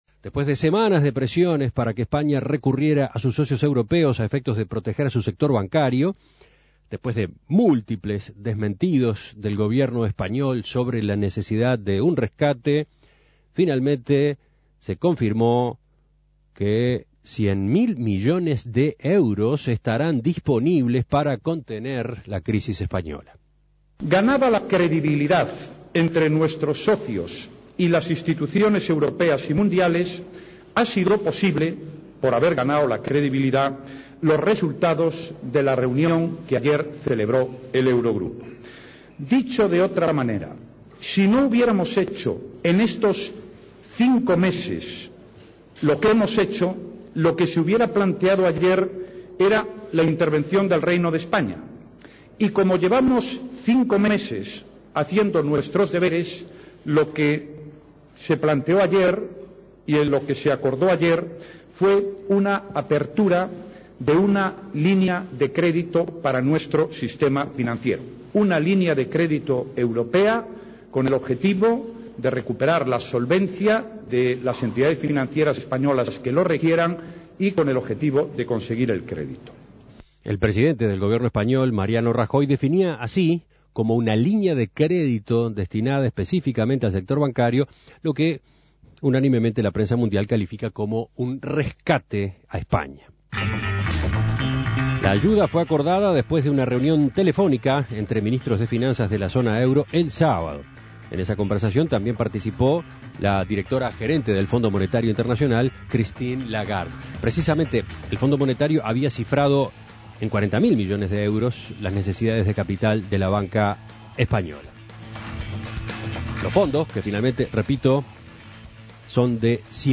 Escuche el contacto con Mario Lubetkin, colaborador de En Perspectiva desde Roma.